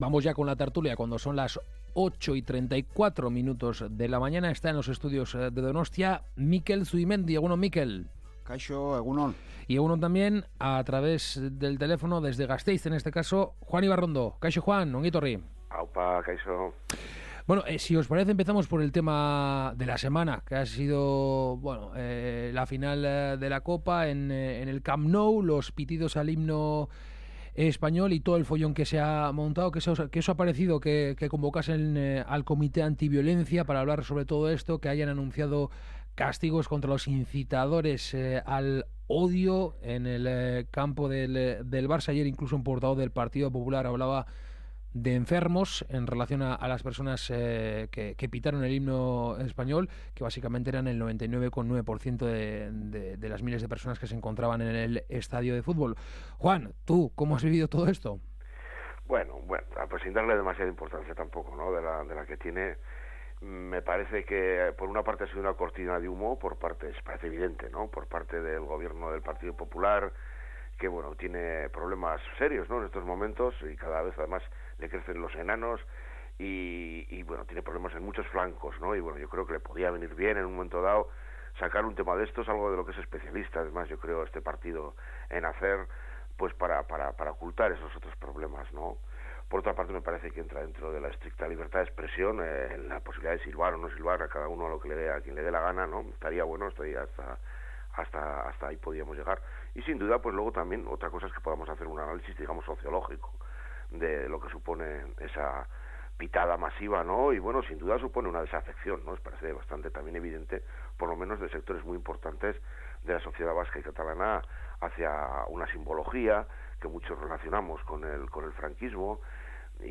La tertulia de Kalegorrian